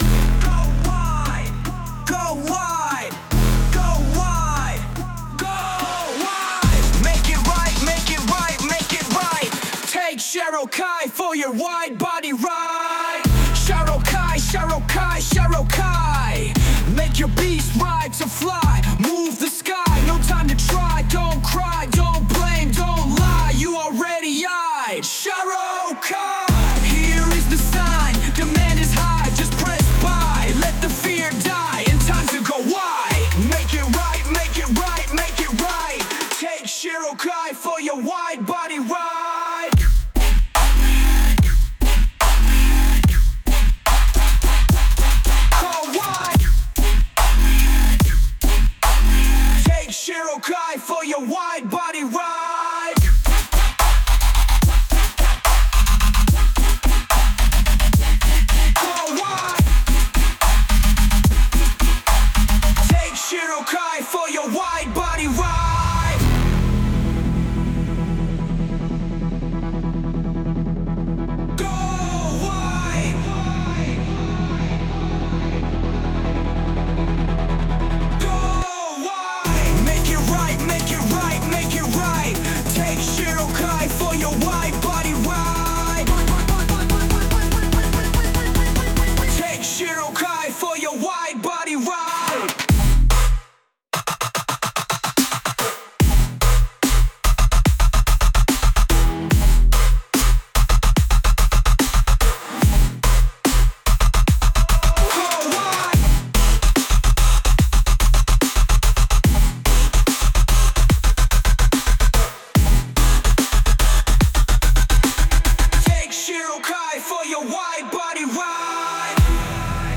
DUB STEP/GRIME